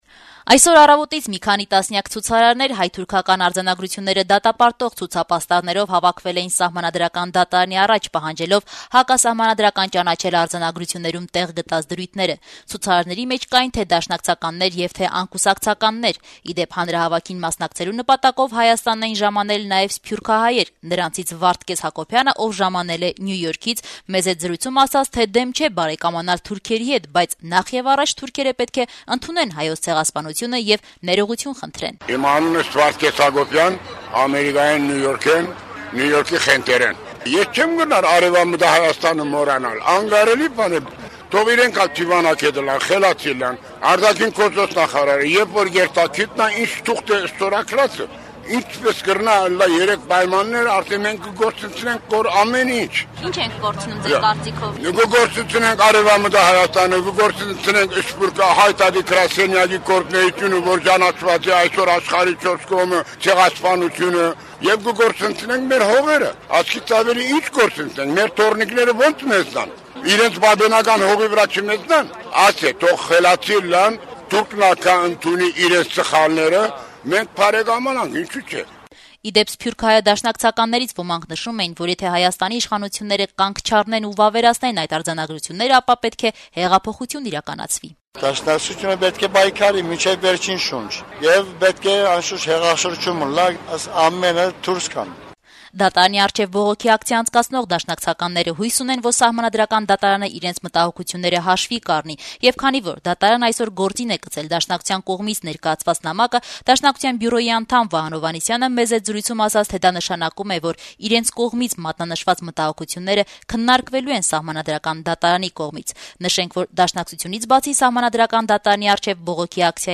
Բողոքի ցույց Սահմանադրական դատարանի մոտ
Մինչ Սահմանադրական դատարանը քննում էր անցած տարվա հոտեմբերի 10-ին ստորագրված հայ-թուրքական արձանագրությունների համապատասխանությունը Հայաստանի Հանրապետության Սահմանադրությանը, դատարանի շենքի մոտ հավաքված մի քանի տասնյակ մարդիկ պահանջում էին հակասահմանադրական ճանաչել արձանագրություններում տեղ գտած դրույթները